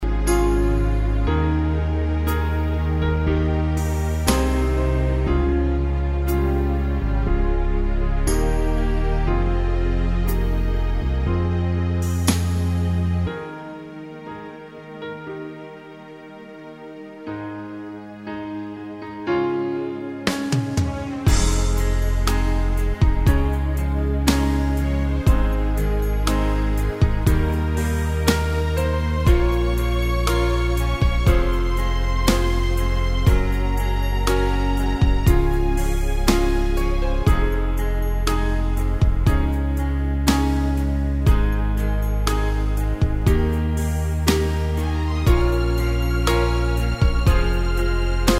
High Key without Background Vocal